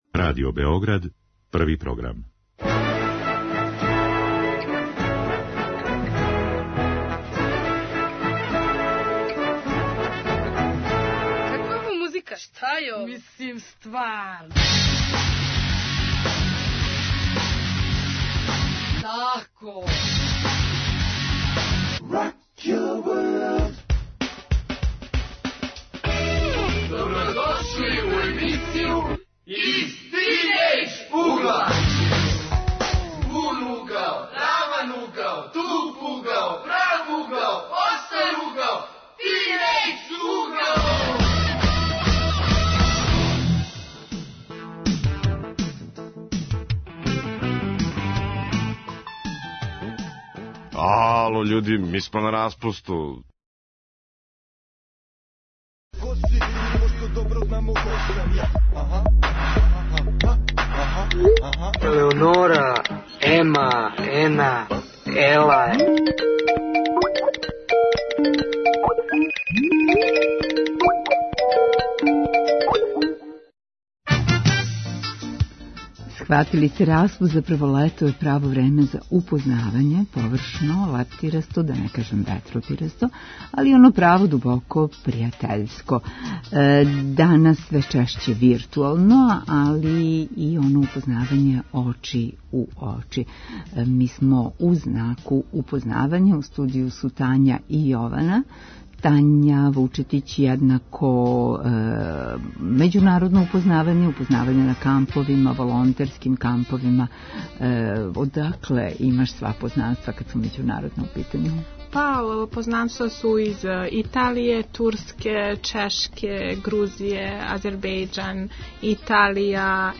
Гости су тинејџери